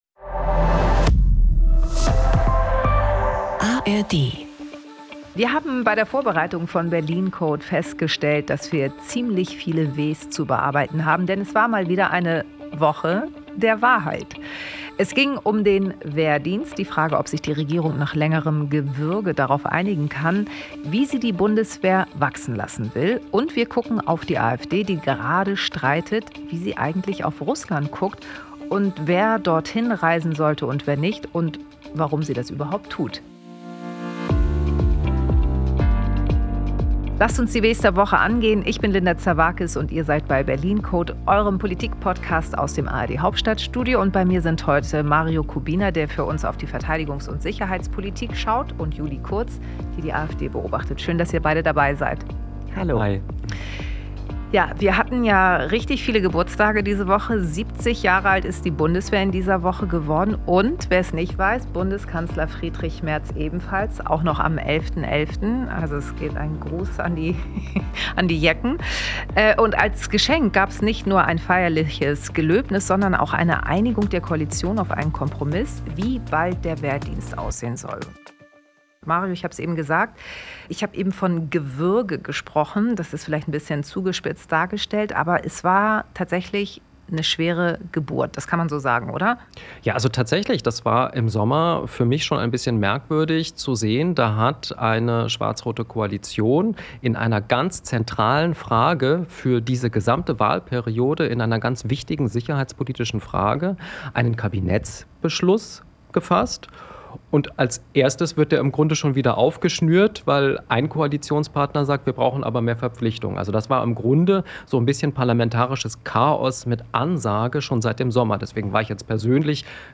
Darüber sprechen wir heute im Berlin Code, eurem Politikpodcast aus dem ARD-Hauptstadtstudio.
Linda Zervakis schaut mit den ARD-Korrespondentinnen und -korrespondenten jede Woche hinter die Kulissen der Bundespolitik.